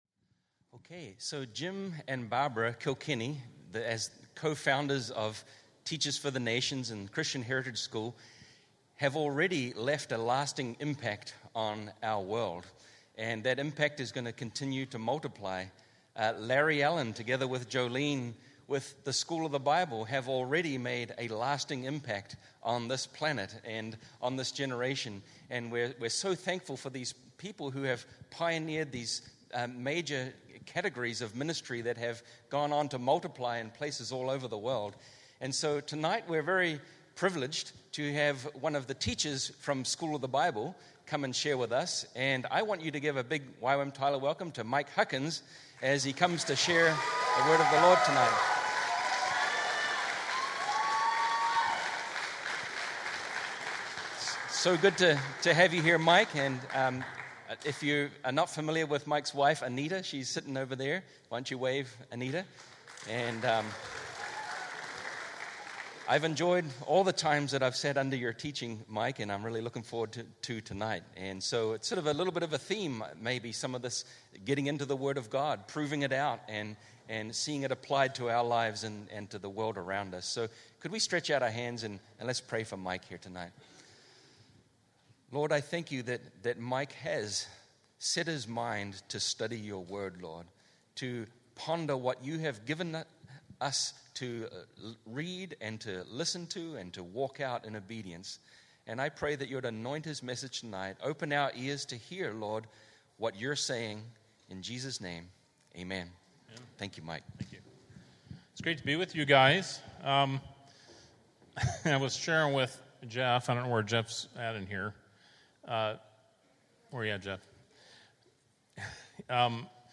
Speaking at YWAM Tyler Family Night